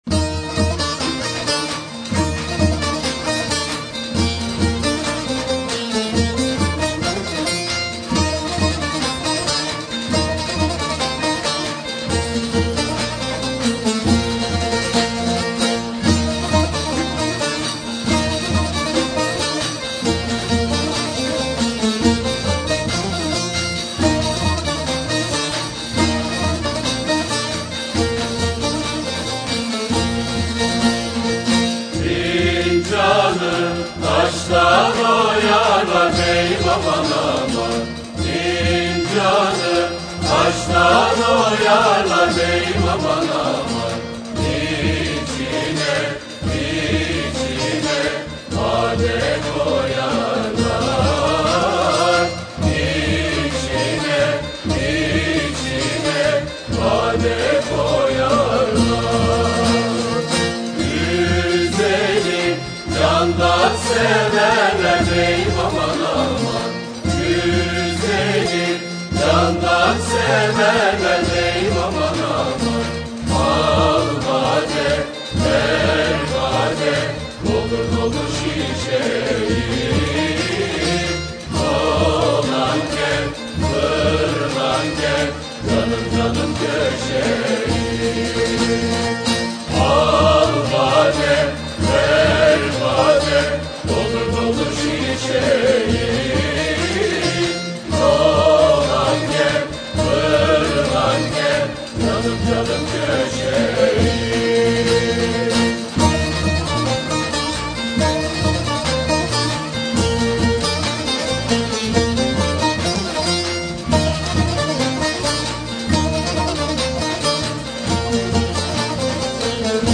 Etiketler: türkiye, türkü